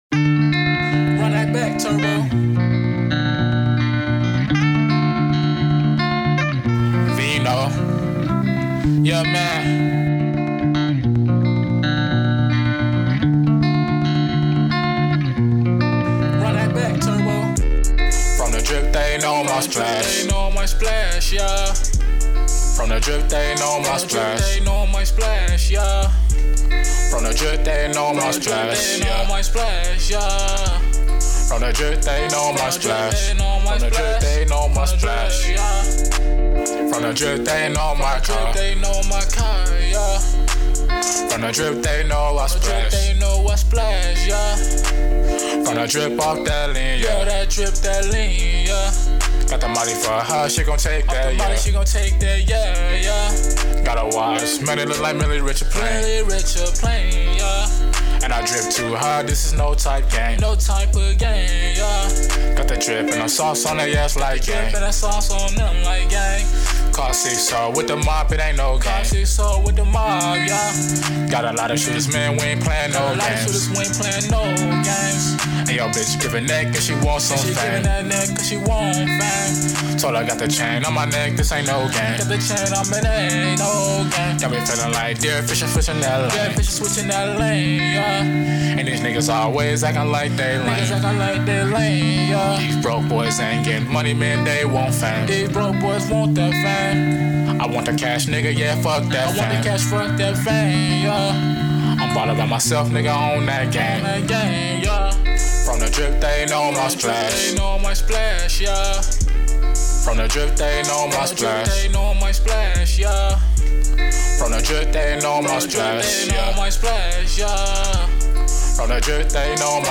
R&B, HipHop, Trap, & Melodic Poject!